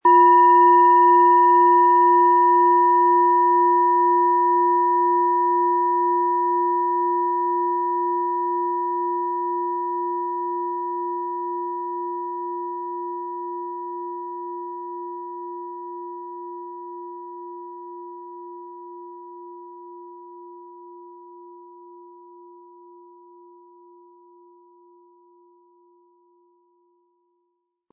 Tibetische Herz-Bauch-Kopf- und Schulter-Klangschale, Ø 17,5 cm, 600-700 Gramm, mit Klöppel
Mit Klöppel, den Sie umsonst erhalten, er lässt die Planeten-Klangschale voll und harmonisch erklingen.
HerstellungIn Handarbeit getrieben
MaterialBronze